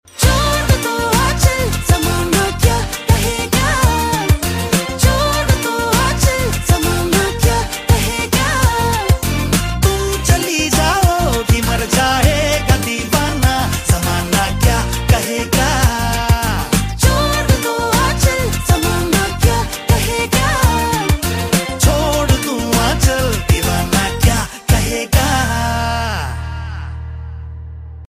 Bollywood & Indian